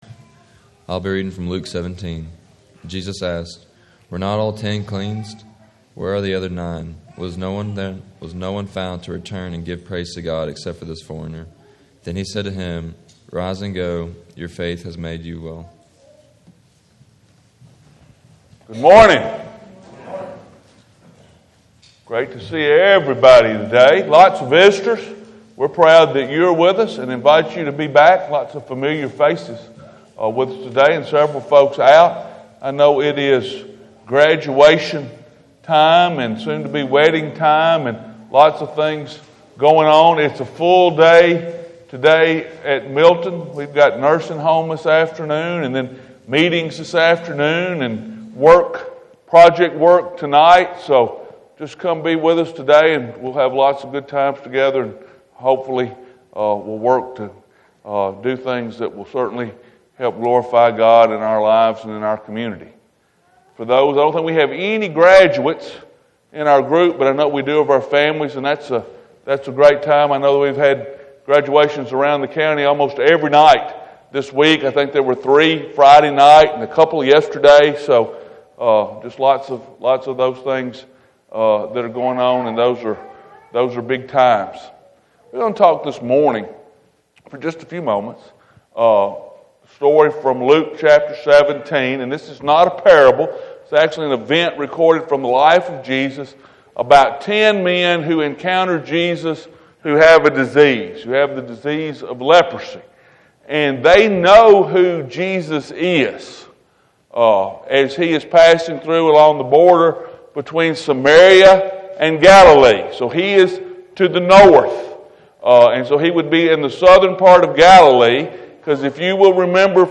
Sunday Morning May 17th, 2015. Do we thank God for the healing from sin that he provides through Jesus?
Posted in All Sermons , Sunday Morning